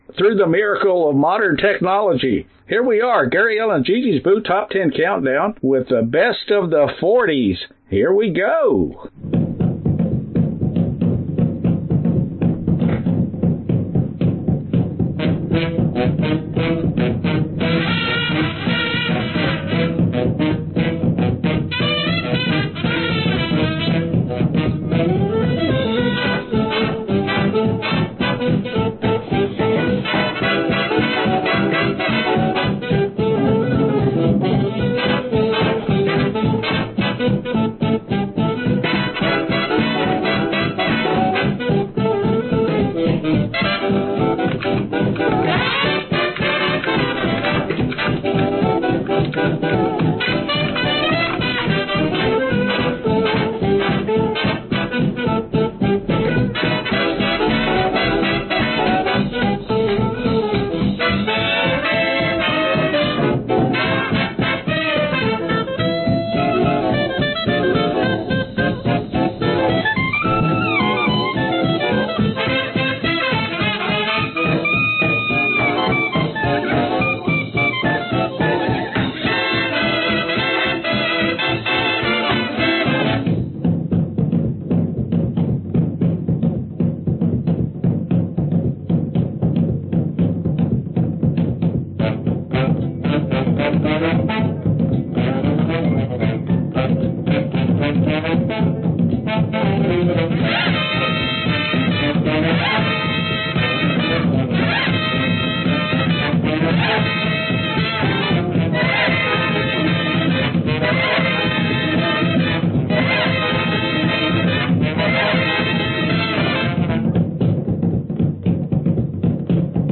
Genre Oldies